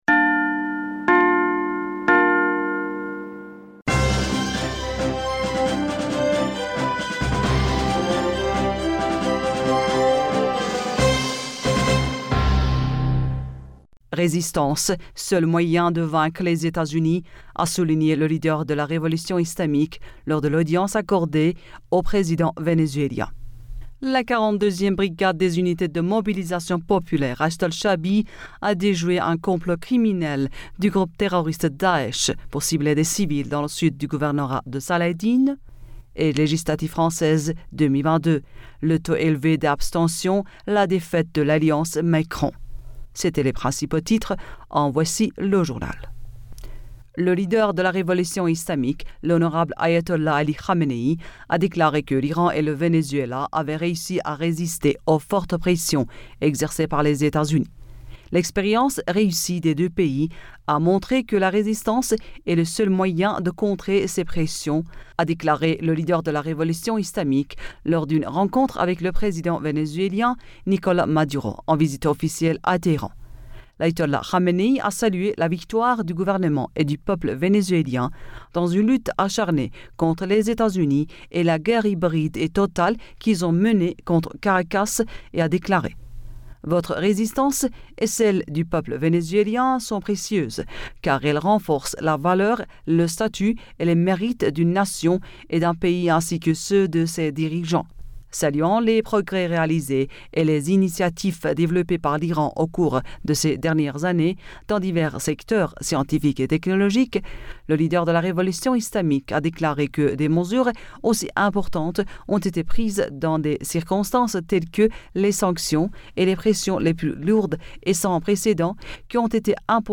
Bulletin d'information Du 13 Juin